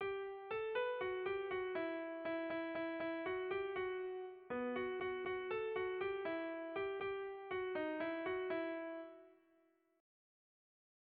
Erromantzea
A-B